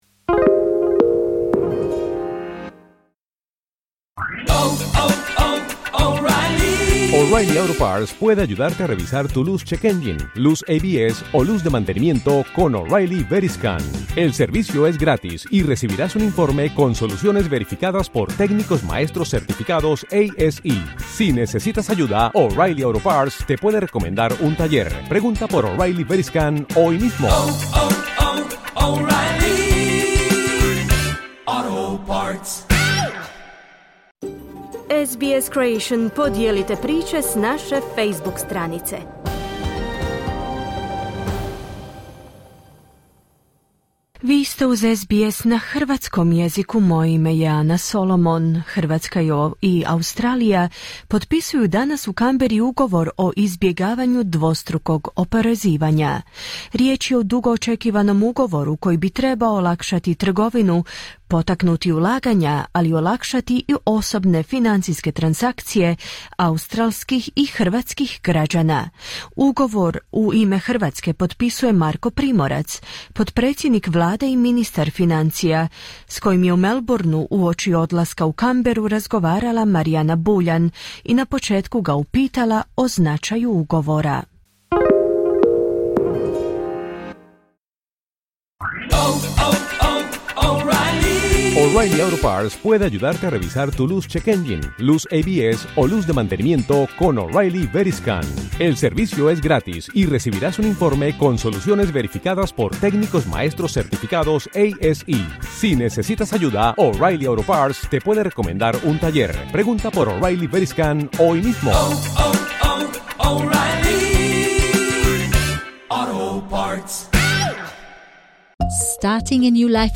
Rizničar australske vlade Jim Chalmers i Marko Primorac, potpredsjednik hrvatske vlade i ministar financija, nazočili su u Canberri potpisivanju Ugovora o izbjegavanju dvostrukog oporezivanja. Poslušajte što je ministar Primorac kazao o važnosti sporazuma i najnovijim financijskim pokazateljima u Hrvatskoj.